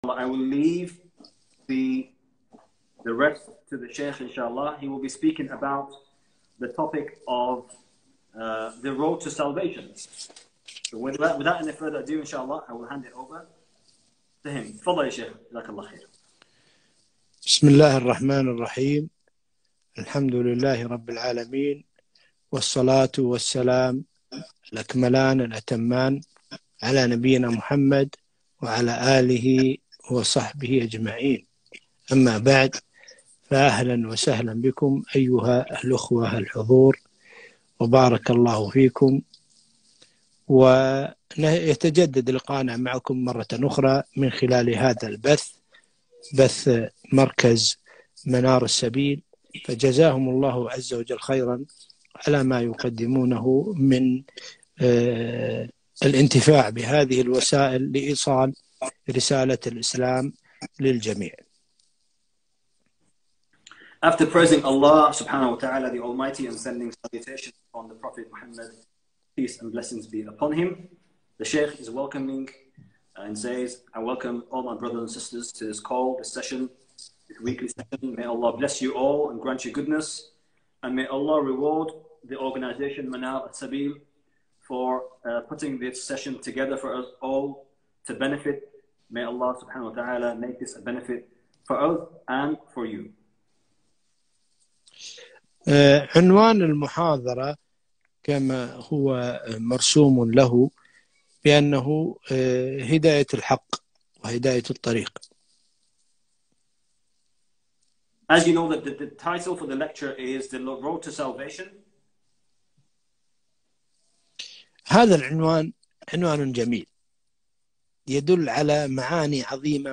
محاضرة دعوية عن « الاسلام » مترجمة باللغة الانجليزية